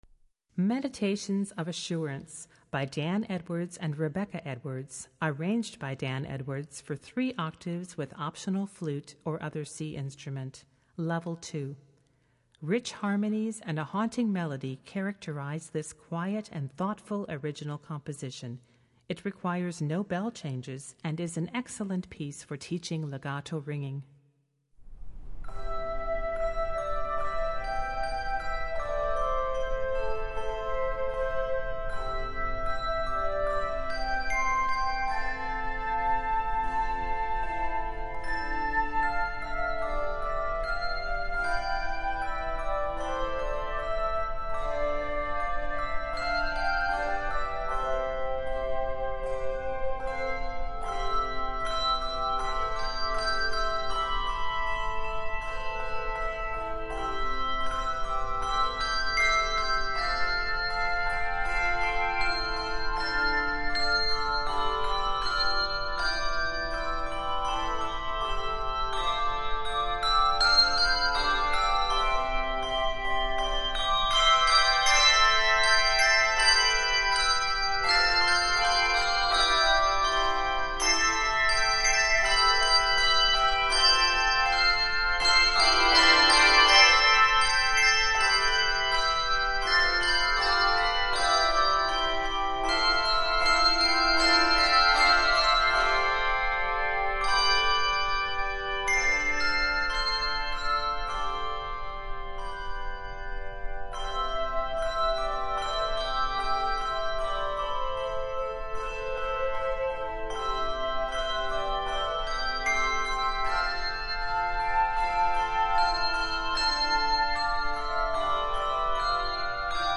Voicing: Handbells 3 Octave